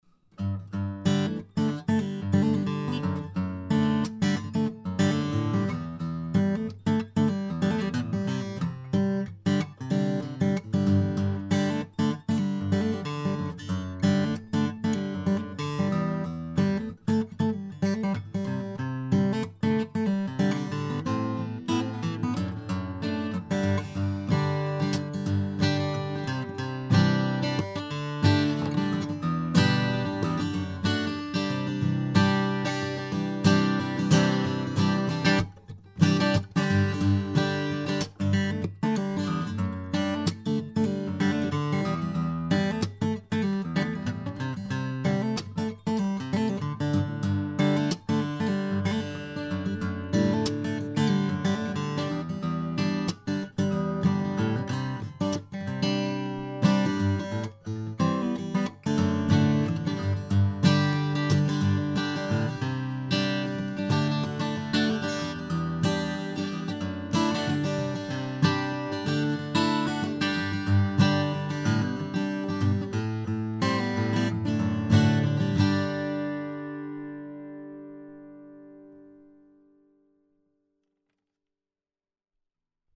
Струны были совершенно новыми, и я пытался дать им пару минут игры. Струны немного яркие, играют немного агрессивно, но это даст вам представление о том, как звучат микрофоны.